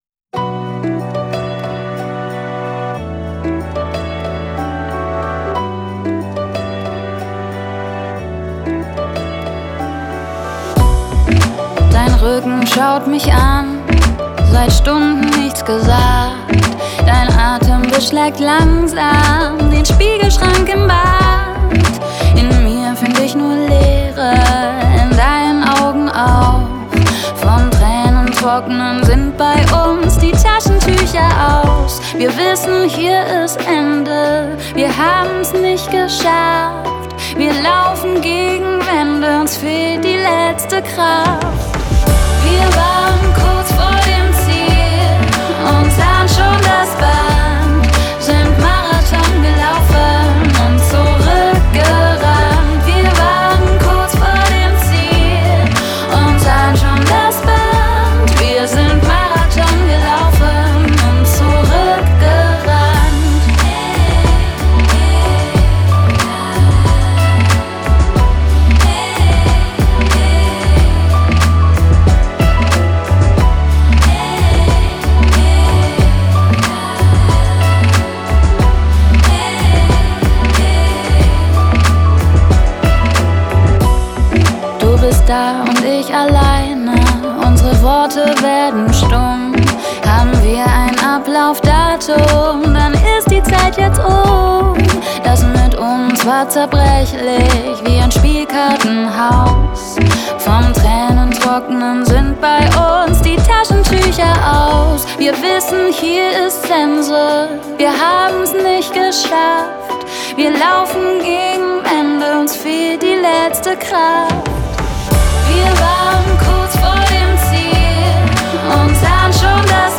отличается динамичными ритмами и современным продакшеном